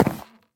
should be correct audio levels.
wood2.ogg